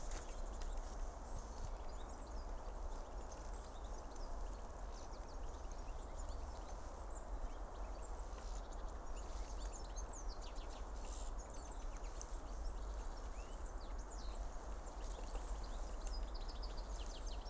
Birds -> Finches ->
Goldfinch, Carduelis carduelis
Administratīvā teritorijaRīga